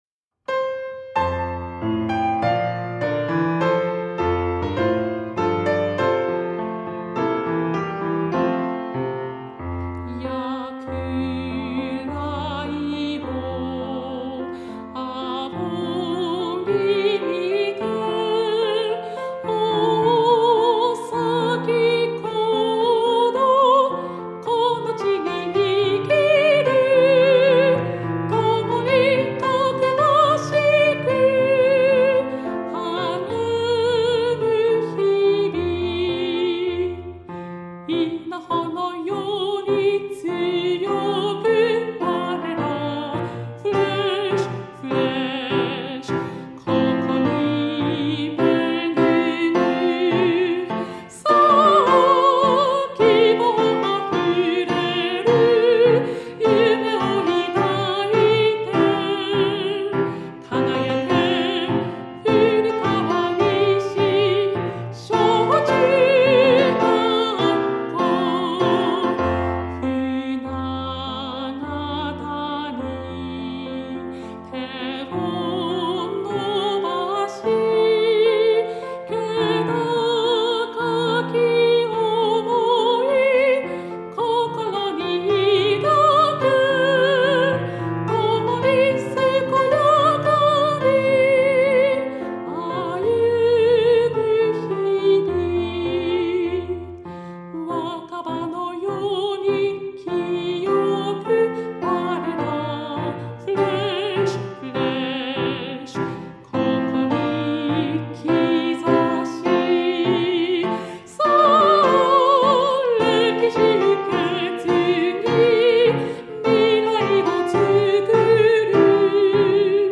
古川西小中学校 校歌（歌+ピアノ） (音声ファイル: 3.0MB)